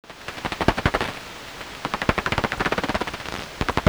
The Myotis bats like the Daubenton’s, all sound rather similar, generally coming out as a regular series of  dry “clicks” when listened to with the bat detector set to 35 to 50 kHz.
The Daubenton’s, whiskered and Brandt’s bats have medium repetition rates but the Natterer’s bat tends to be faster, quieter and more irregular.
These are Daubenton’s calls as heard on a heterodyne bat detector set to 45kHz.
dbfeedingfast.mp3